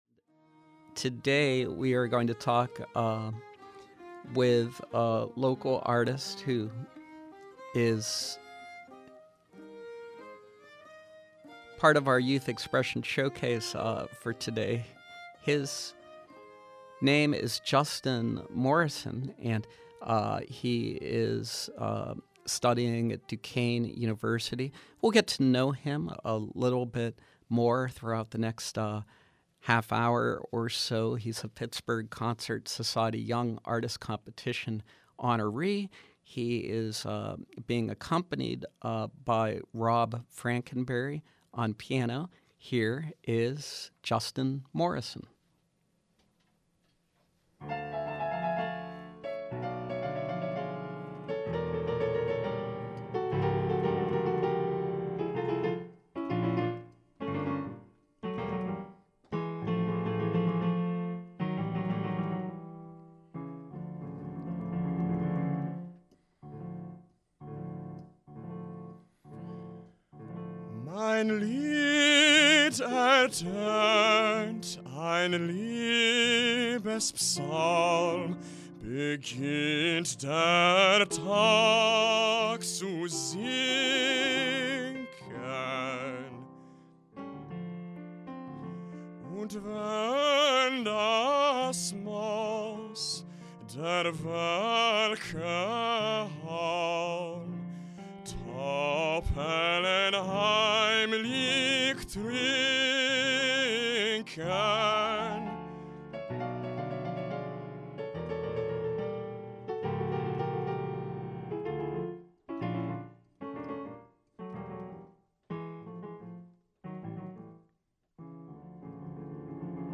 performing selections for voice